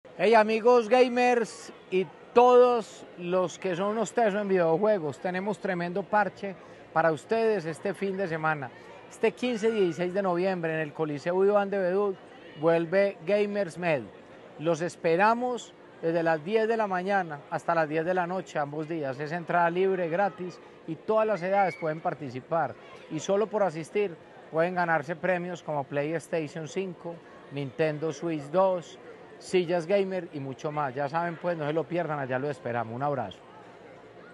Declaraciones-alcalde-de-Medellin-Federico-Gutierrez-Zuluaga.mp3